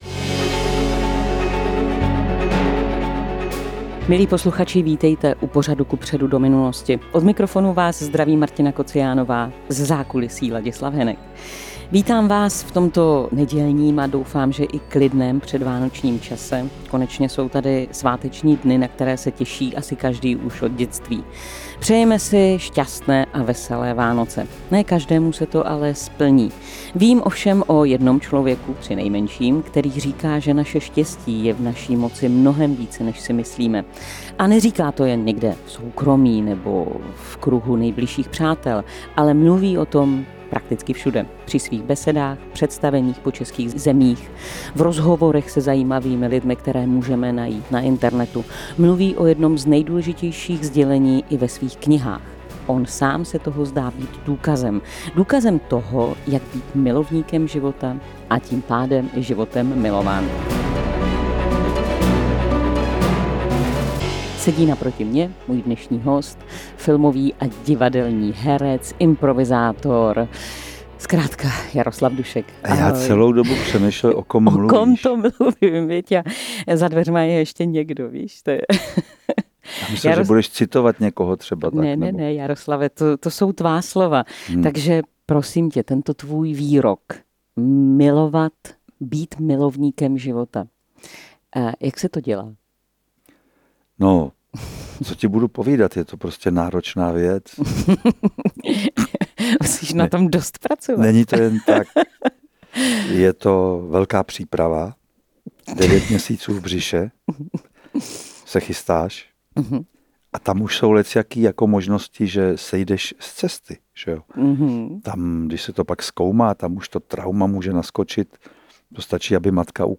rozhovoru s divadelním a filmovým hercem, spisovatelem a klidným člověkem, Jaroslavem Duškem.